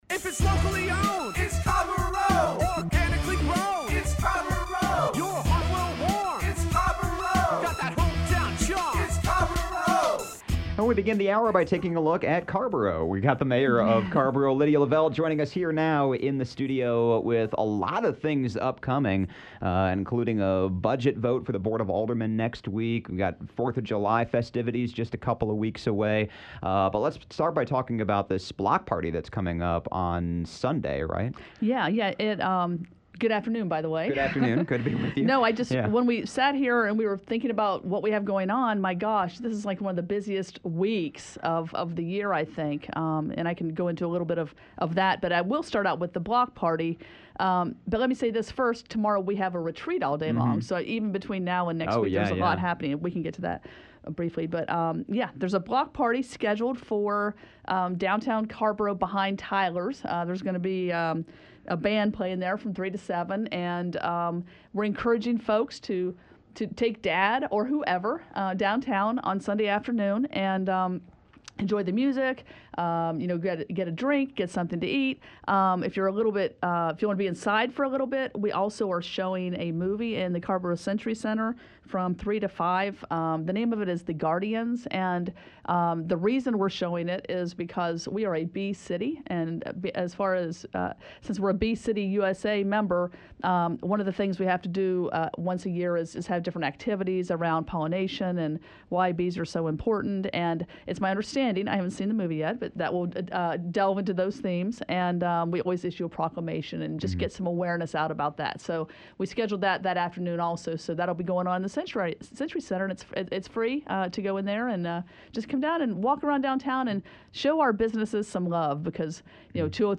Week of June 11, 2018 – Conversations with the Mayors